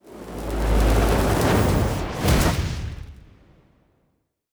Fire Spelll 33.wav